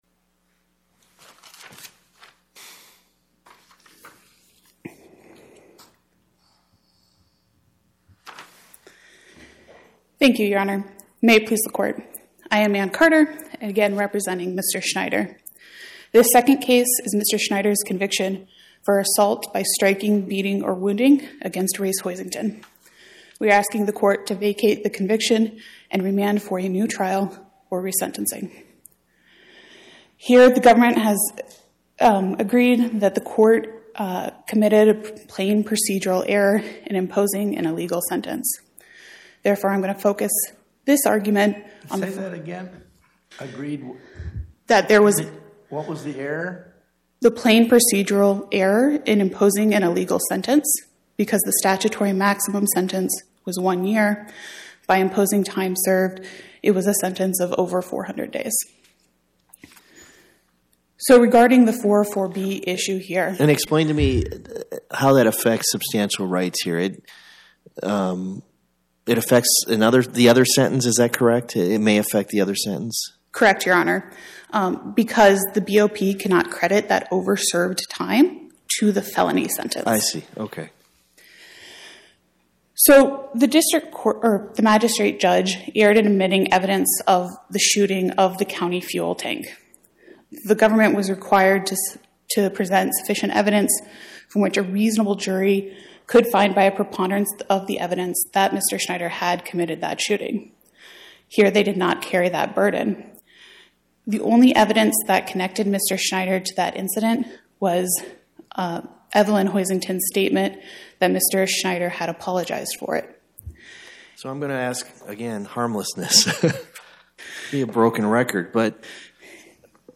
Oral argument argued before the Eighth Circuit U.S. Court of Appeals on or about 12/19/2025